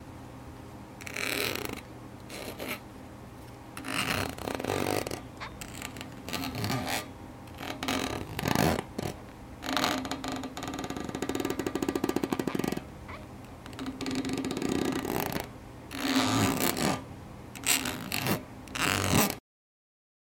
石头金属拖动
描述：岩石和金属刮擦和拖动。
标签： 拖动 岩石 研磨 摩擦 金属 石材
声道立体声